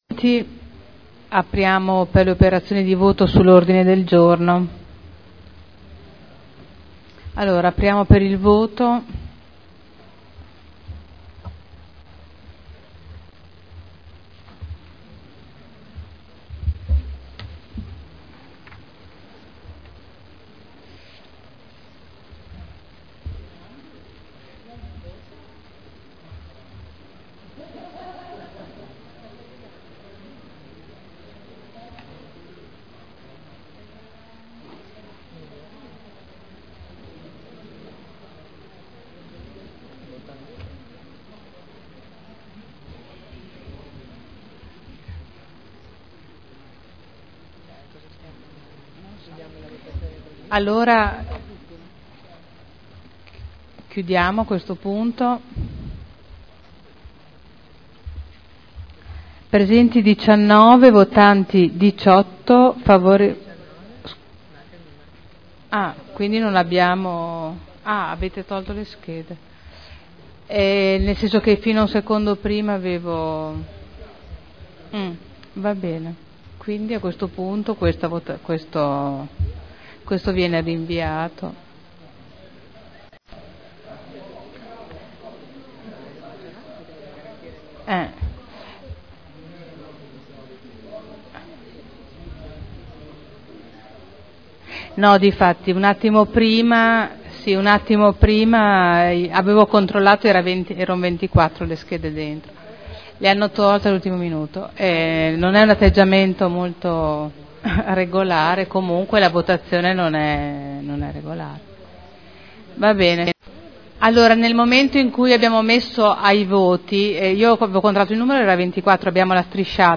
Presidente — Sito Audio Consiglio Comunale